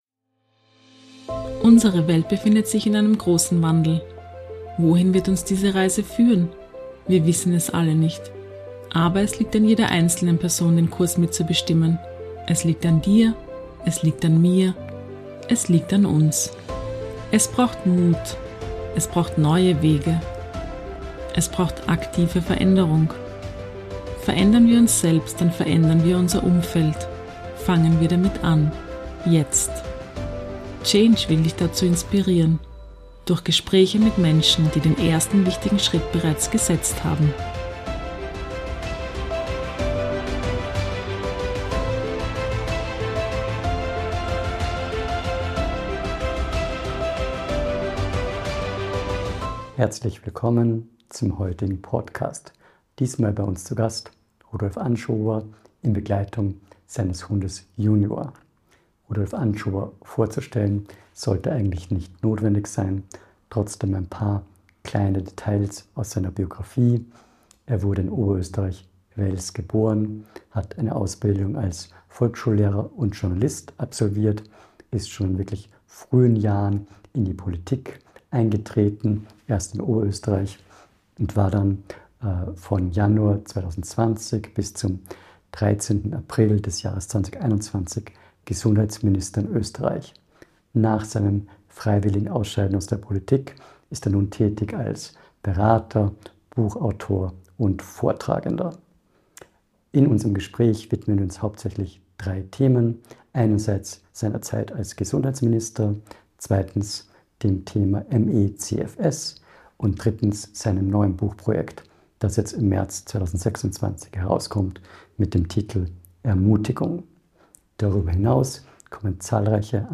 im Gespräch mit Rudi Anschober ~ CHANGE - INSPIRATION FÜR DEN WANDEL Podcast